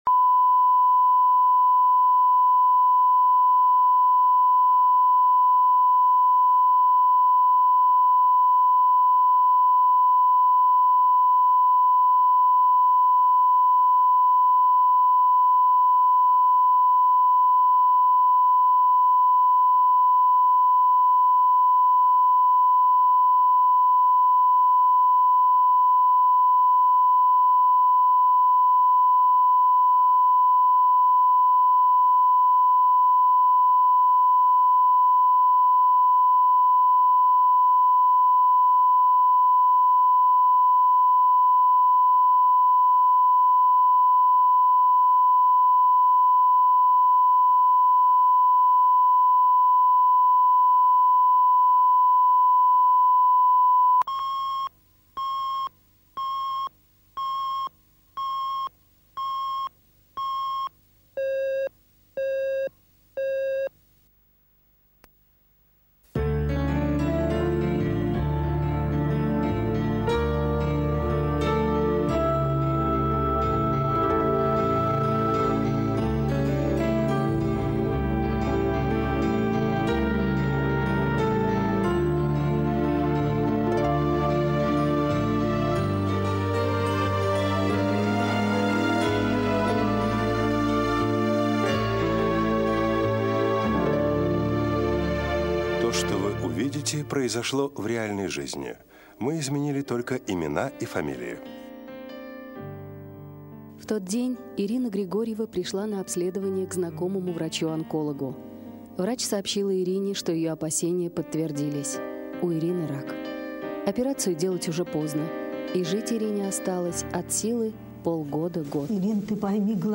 Аудиокнига Женить мужа | Библиотека аудиокниг